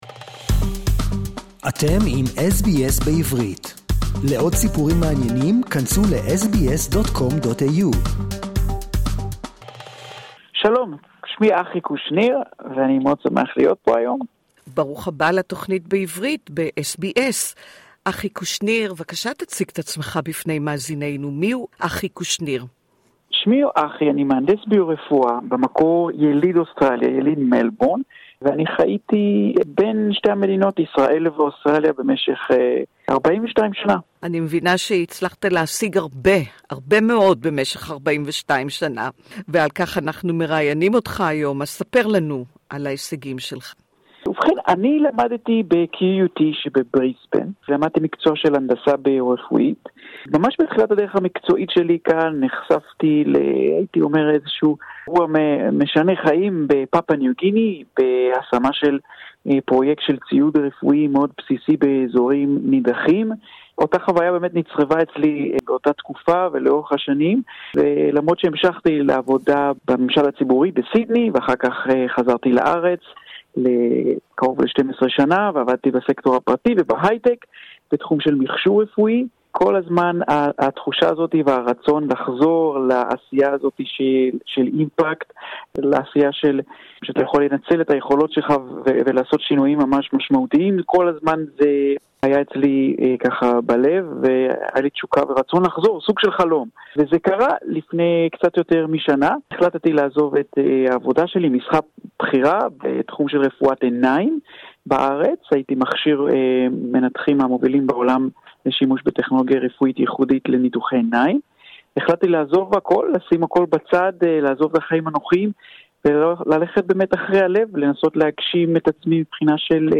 (Interview in Hebrew) Share